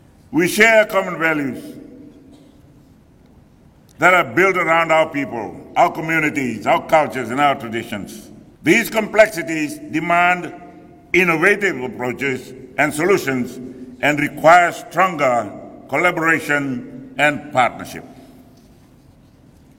This was said by Prime Minister Sitiveni Rabuka in his opening address at the Pacific Islands Forum Special Leaders Retreat welcome dinner in Nadi last night.
Prime Minister Sitiveni Rabuka.